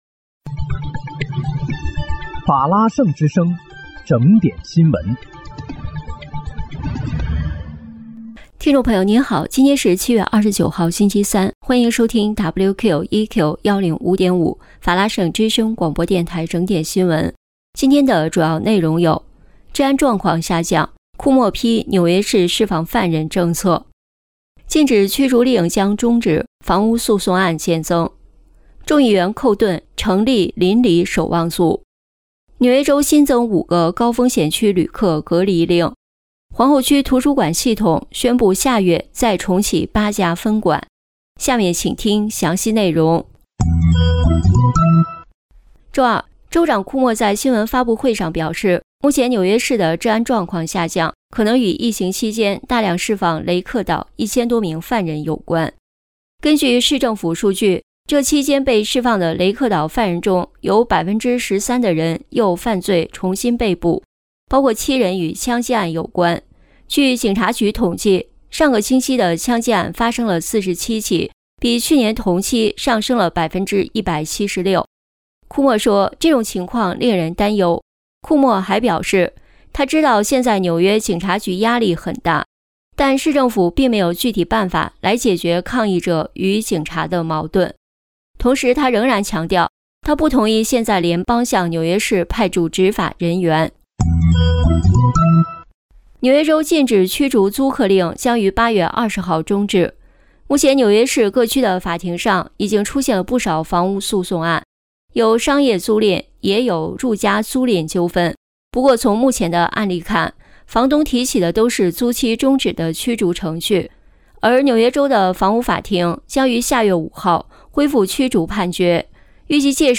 7月29日（星期三）纽约整点新闻